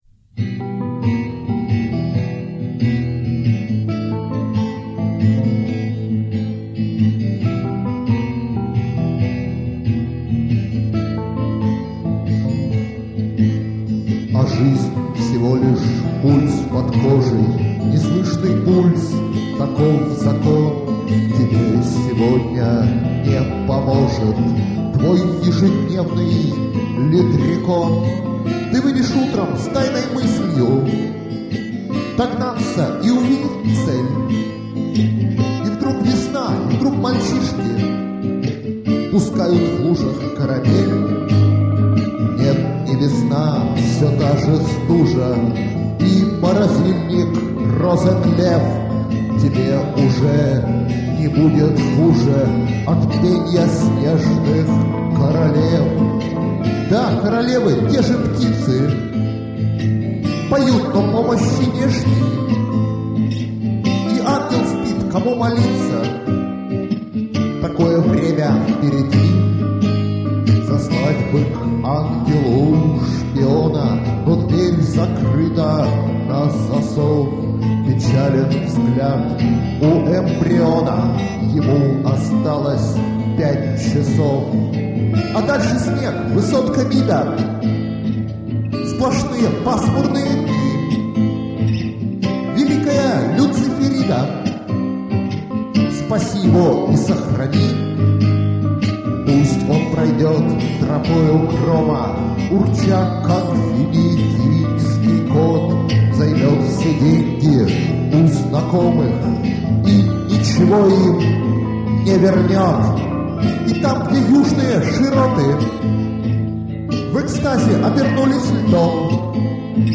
Странная гармония диссонансов.
"Кукольный" голос